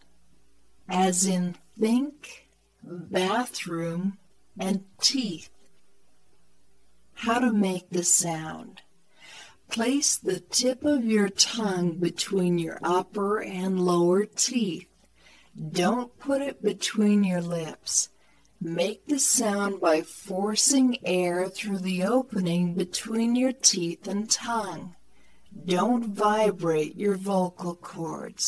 後で紹介するフリーソフトでWaveファイルに変換し、さらに別のフリーソフトで減速させました。
あくまでもフリーソフトなので音質はあまりよくありませんが、聞き取りには十分でしょう。